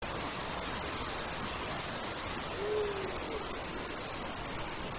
Ūpis, Bubo bubo
StatussDzirdēta balss, saucieni
Piezīmes/Vieta aptuvena, nakts ieraksts.